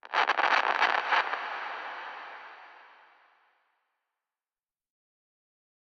Minecraft Version Minecraft Version latest Latest Release | Latest Snapshot latest / assets / minecraft / sounds / ambient / nether / warped_forest / addition1.ogg Compare With Compare With Latest Release | Latest Snapshot